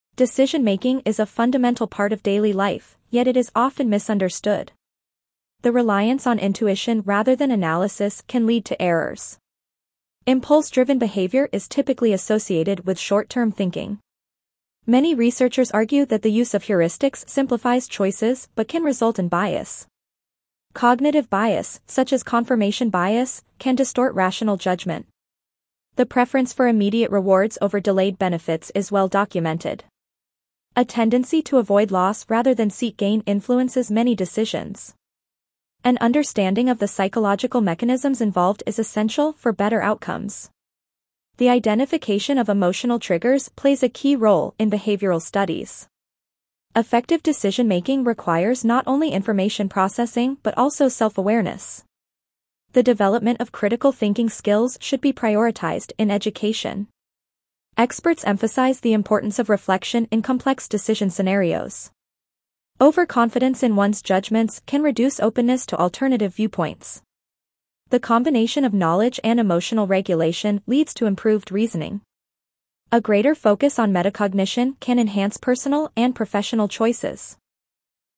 C1 Dictation - Psychology of Decision Making
Your teacher will read the passage aloud.